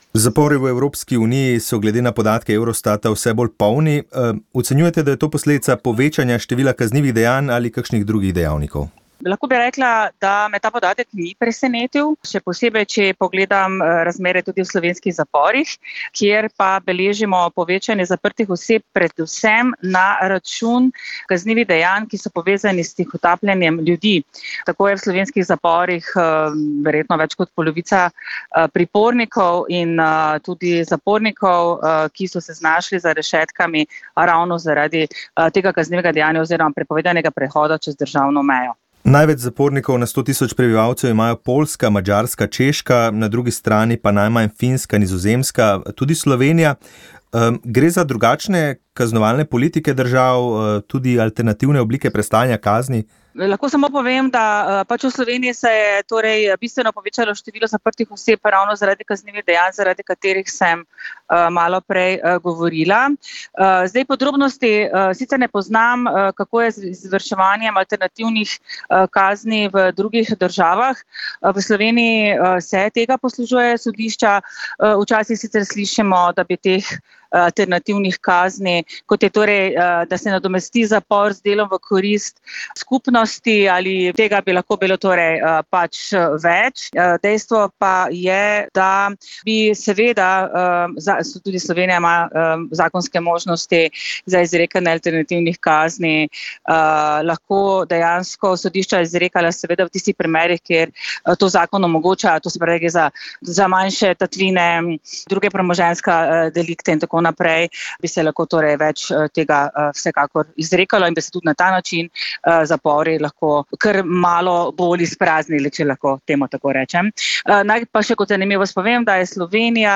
O omenjenem, politični stabilnosti in prihodnji vlogi Izraela je za naš radio spregovoril poznavalec razmer na Bližnjem vzhodu